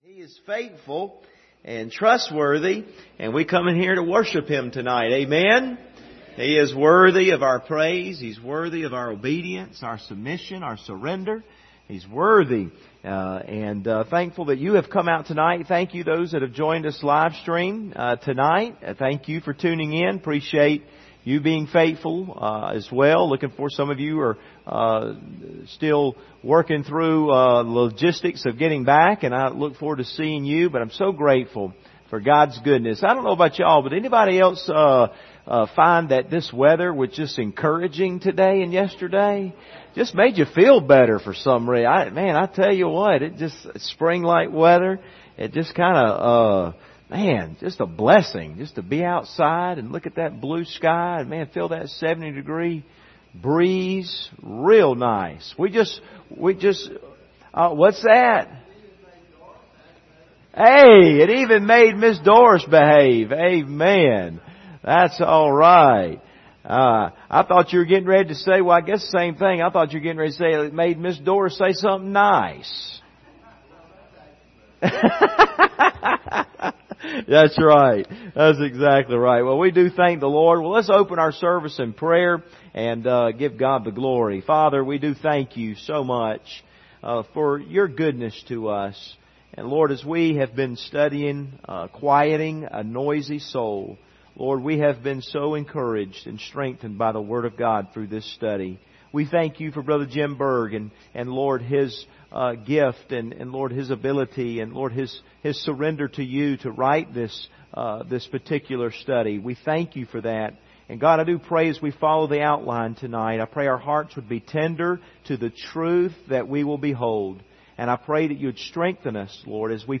Service Type: Wednesday Evening Topics: wisdom of God